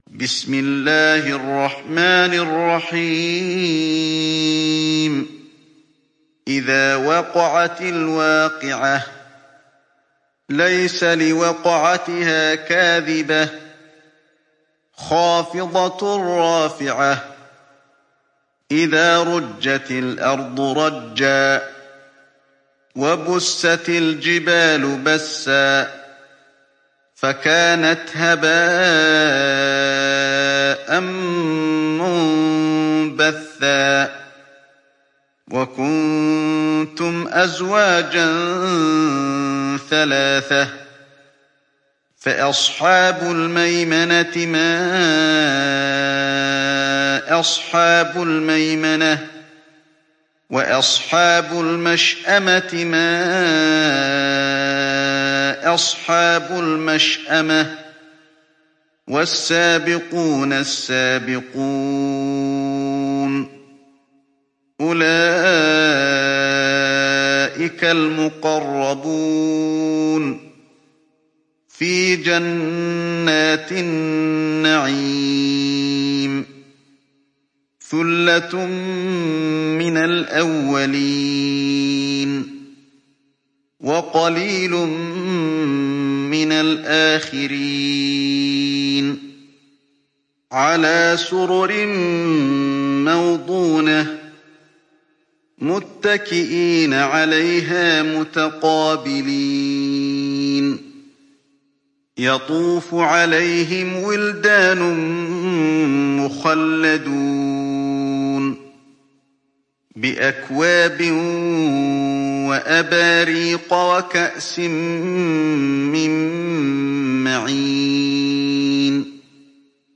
تحميل سورة الواقعة mp3 بصوت علي الحذيفي برواية حفص عن عاصم, تحميل استماع القرآن الكريم على الجوال mp3 كاملا بروابط مباشرة وسريعة